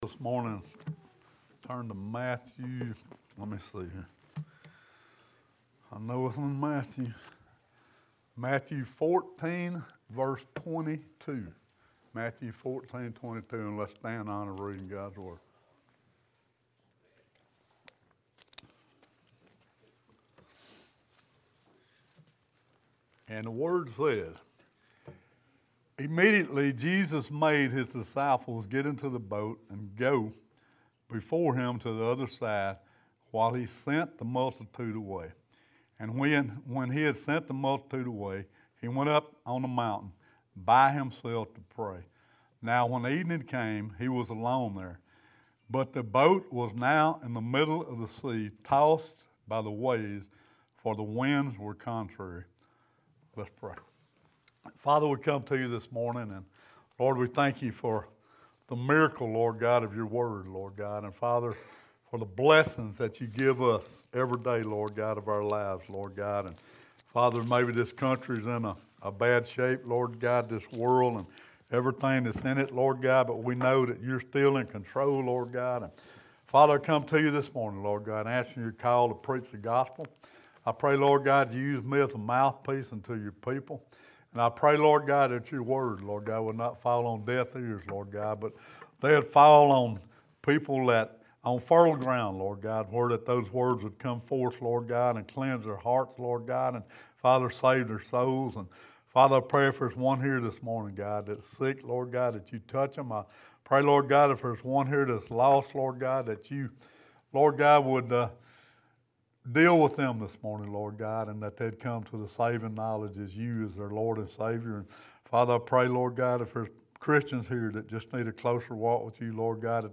Sermon (11-5) Jesus walks on water.All we have too do is Keep Our Eyes on Him Reply Leave a Reply Cancel reply Your email address will not be published.